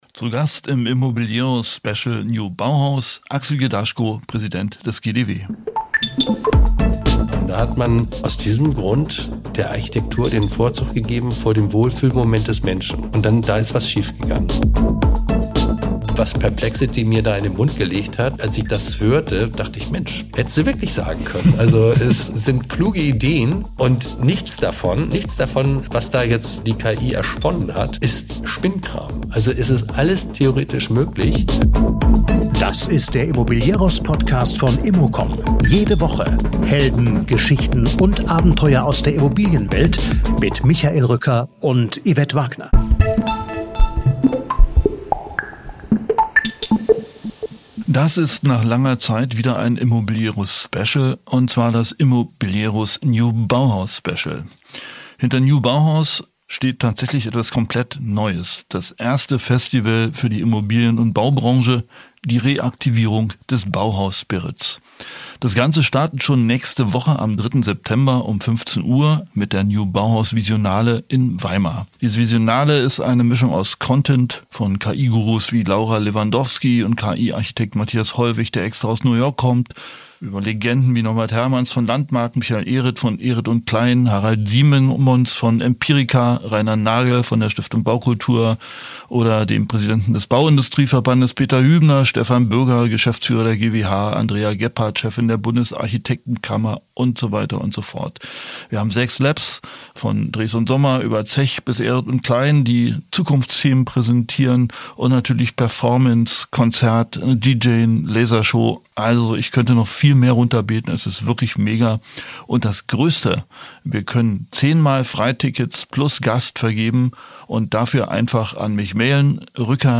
Mit ihm spreche ich über den aktuellen Wahnsinn am Immobilienmarkt, was Bauhaus-Spirit heute ist - über KI und Wohnungswirtschaft und darüber, was perplexity über ihn halluziniert und dabei trotzdem stimmt. Nach einem kurzen Blick auf die aktuelle Wohnungspolitik und den Ruf nach einer „Fastlane fürs Wohnen“ geht es vor allem um die großen Fragen: Wie verändern serielle Bauweisen, digitale Zwillinge und KI-gestützte Architektur die Branche?
Ein Gespräch über Visionen für 2045 – vom Smart Home bis zum selbstfahrenden Auto – und darüber, warum das Menschenrecht auf Wohnen neu gedacht werden muss.